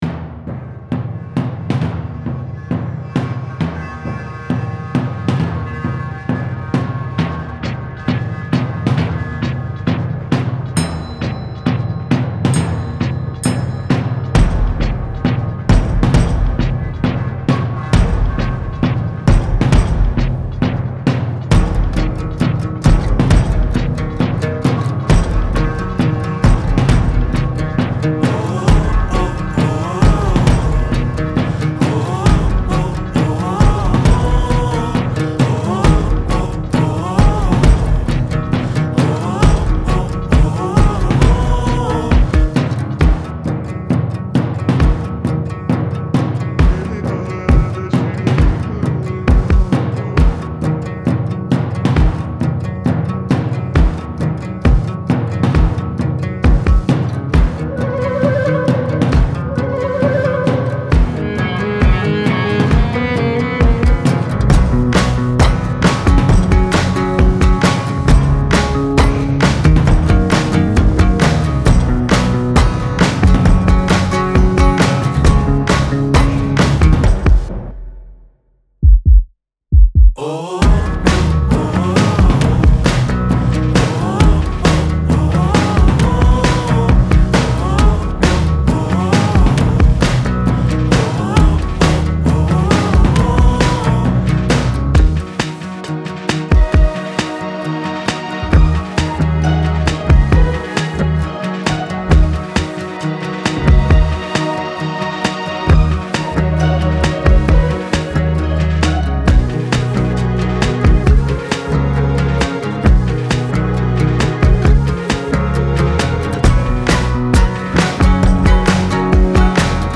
Version instrumentale playback